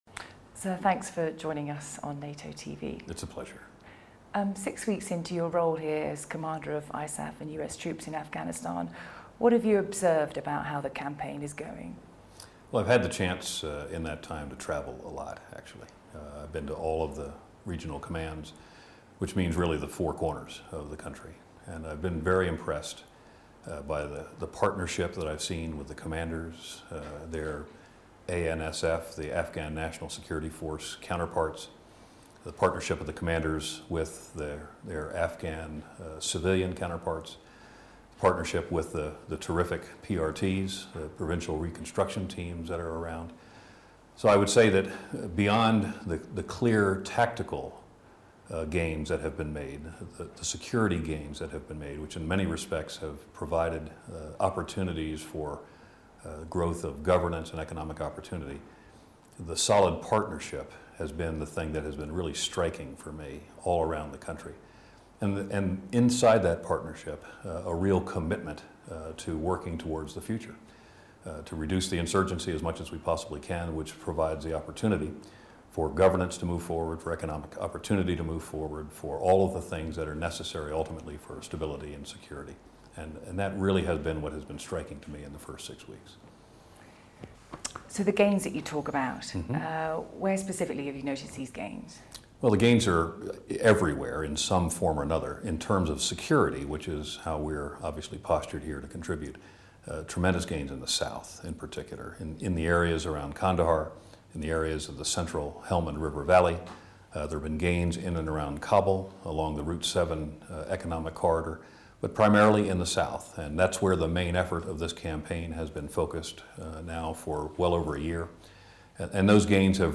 Interview with General John R. Allen, Commander ISAF